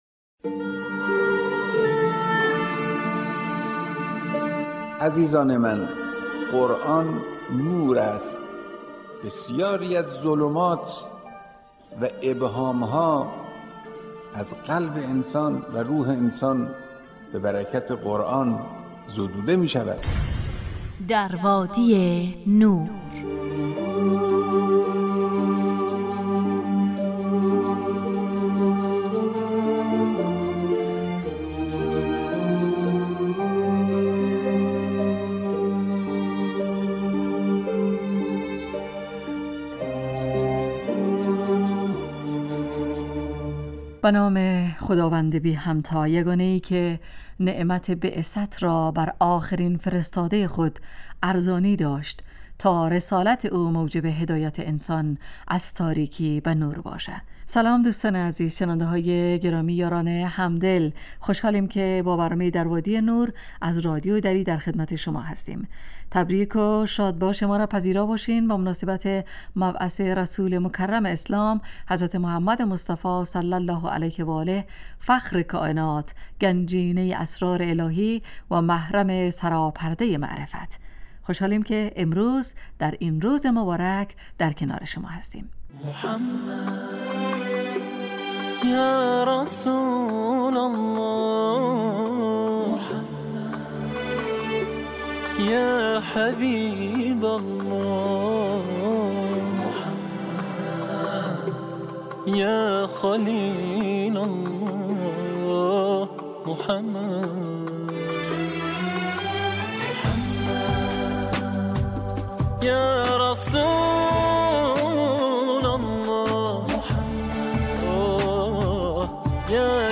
در وادی نور برنامه ای 45 دقیقه ای با موضوعات قرآنی روزهای فرد: ( قرآن و عترت،طلایه داران تلاوت، ایستگاه تلاوت، دانستنیهای قرآنی، تفسیر روان و آموزه های زند...